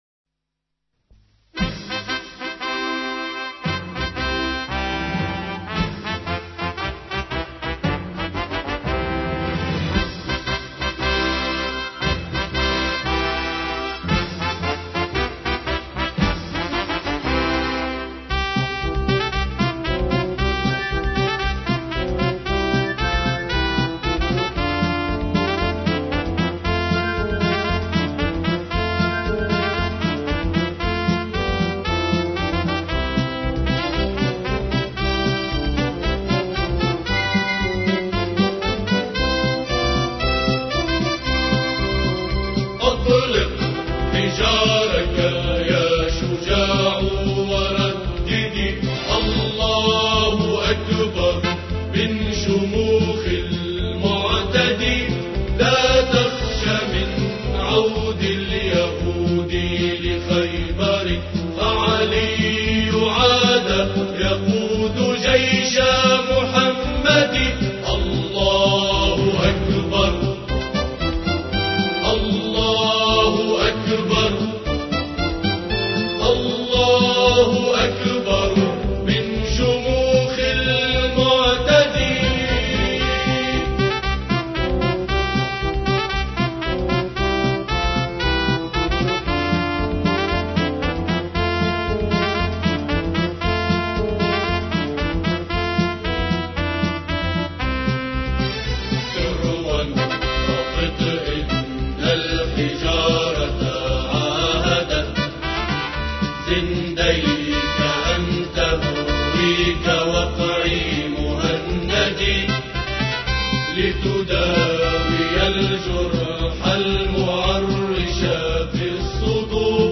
أناشيد لبنانية .... أطليك هجراك